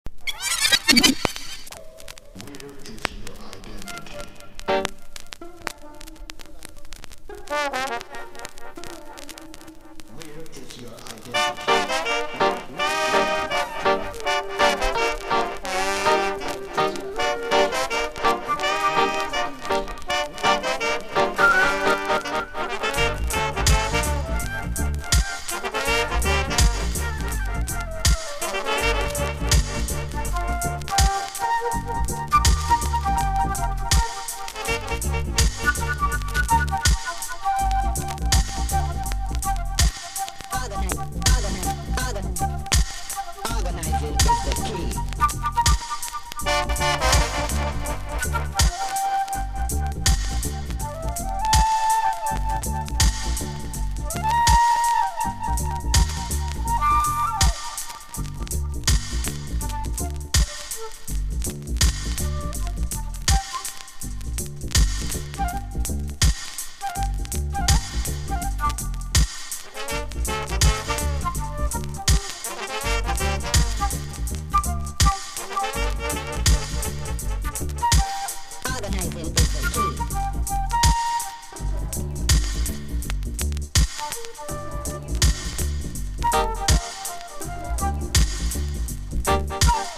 • REGGAE-SKA
ダビーなトラックにフルートが入ったA1、他にも管楽器が入ったダブなど様々な要素がブレンドされた全10曲収録。
DUB / UK DUB / NEW ROOTS